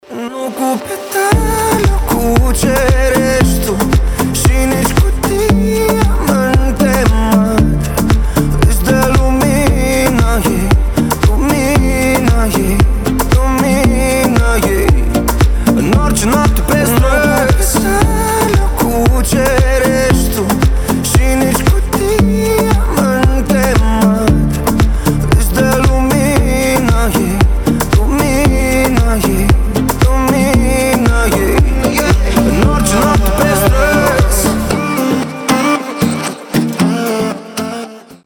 • Качество: 320, Stereo
поп
мужской вокал
приятные